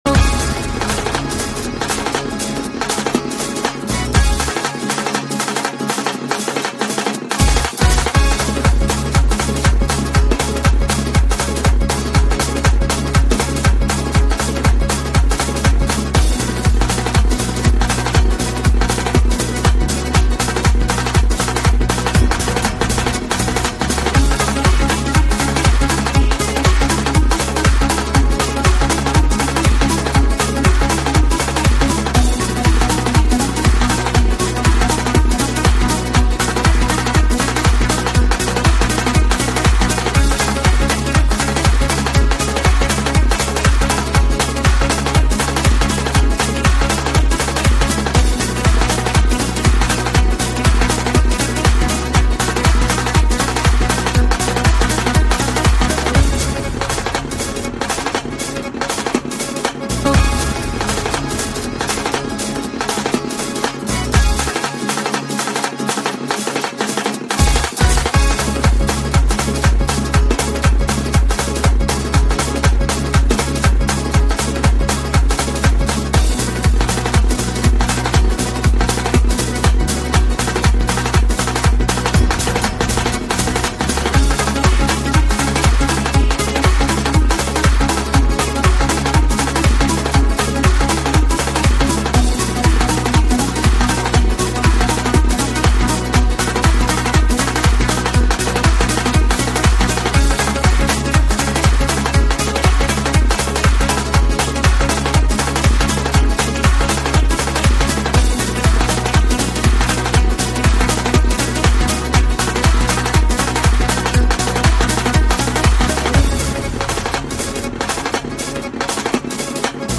• Жанр: Лезгинка музыка